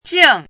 chinese-voice - 汉字语音库
jing4.mp3